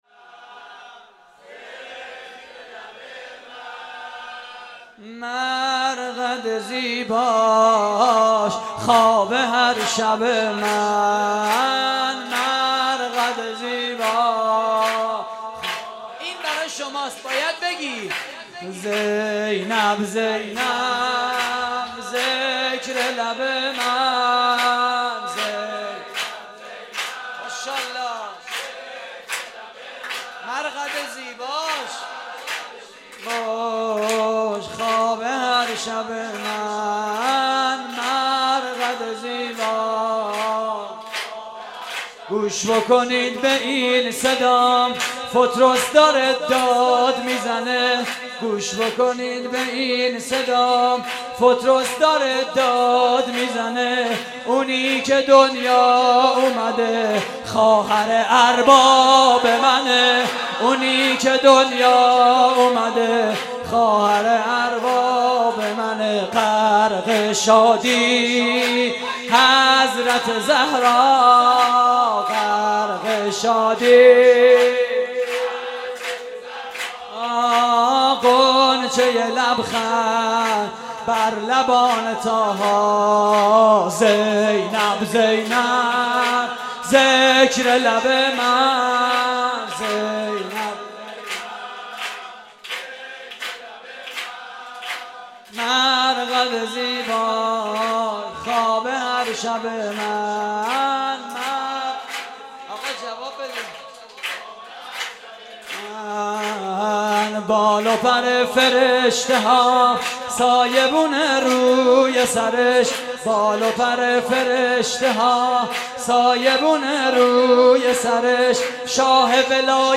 سرود: زینب زینب
مراسم جشن ولادت حضرت زینب (س)